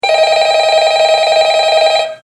На этой странице собраны звуки из культовой хоррор-игры Five Nights at Freddy's. Слушайте онлайн или скачивайте бесплатно в mp3 самые узнаваемые аудиоэффекты: пугающие скримеры, жуткие голоса аниматроников, телефонные звонки Охранника и фоновые шумы пиццерии.
Повторный телефонный звонок